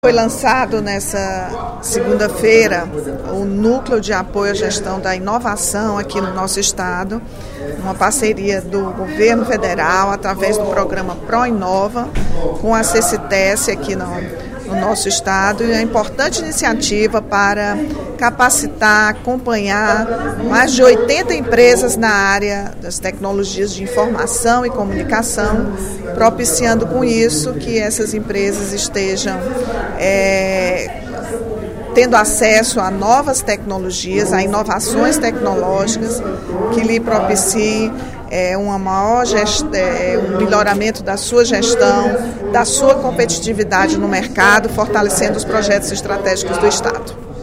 A deputada Rachel Marques (PT), em pronunciamento durante o primeiro expediente da sessão plenária desta quarta-feira (13/03), rebateu as críticas do deputado Fernando Hugo (PSDB) sobre a Petrobras, feitas na sessão de hoje.